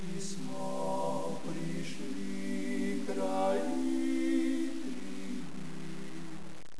The songs were sung by different choirs as: Ljubljanski oktet, Slovenski komorni zbor, Deseti brat oktet,